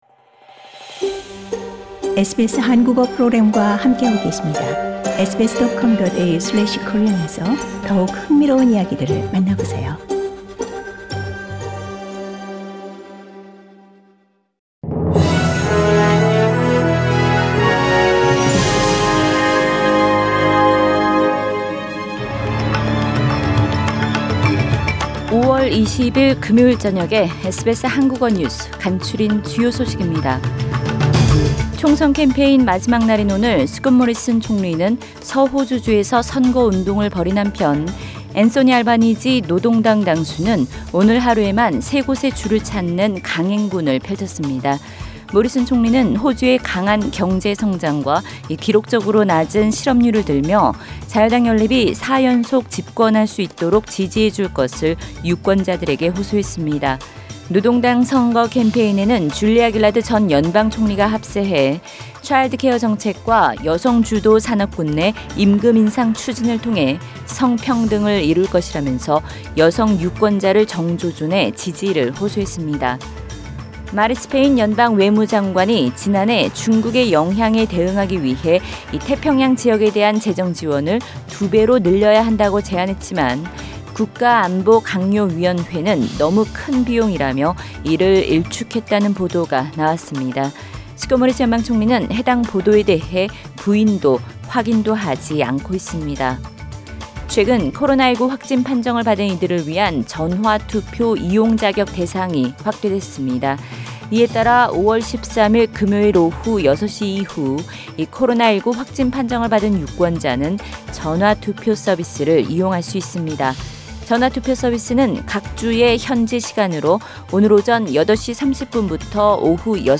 SBS 한국어 저녁 뉴스: 2022년 5월 20일 금요일